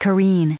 added pronounciation and merriam webster audio
638_careen.ogg